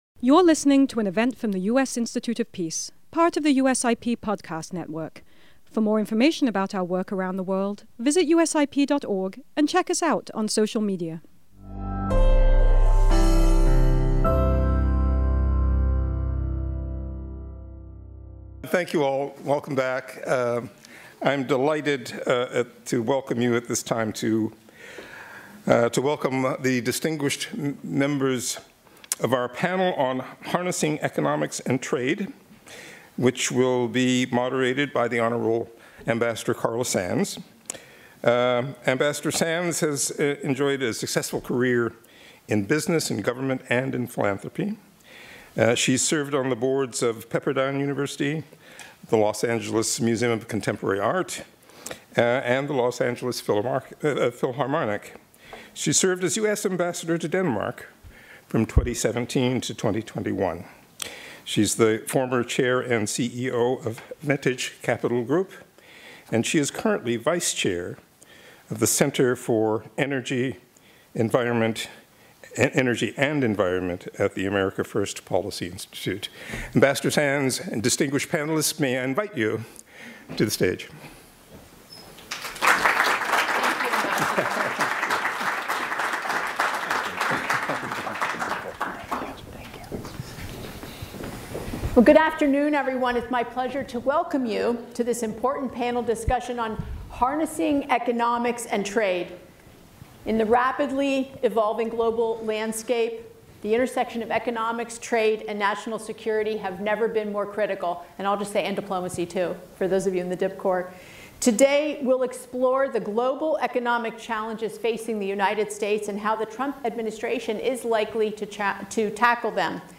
This flagship, bipartisan event brought together national security leaders from across the political spectrum to mark the peaceful transfer of power and the bipartisan character of American foreign policy. Panel Discussion